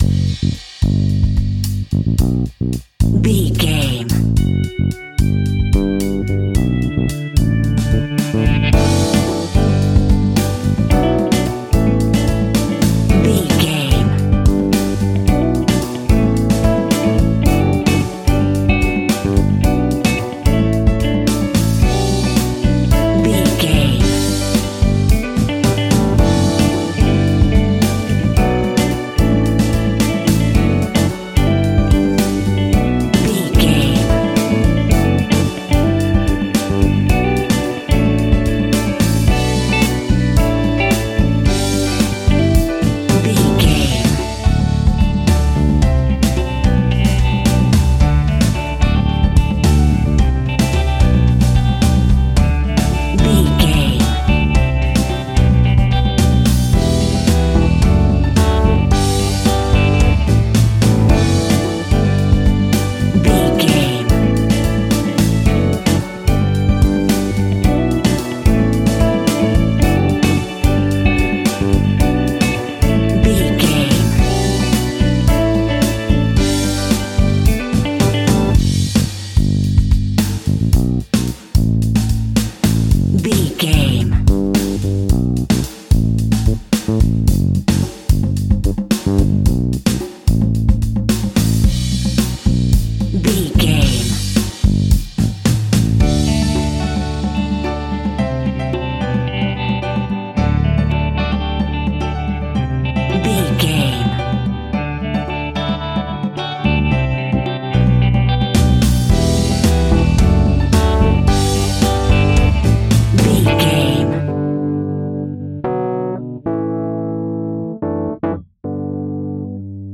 Ionian/Major
D
cheesy
electro pop
pop rock
bold
happy
peppy
upbeat
bright
bouncy
drums
bass guitar
electric guitar
keyboards
hammond organ
acoustic guitar
percussion